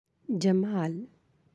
(jamaal)